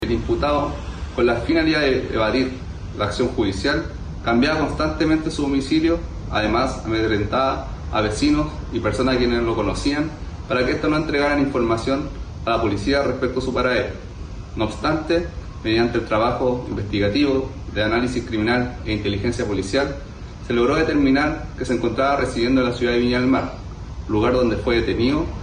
cu-detenido-san-antonio-pdi.mp3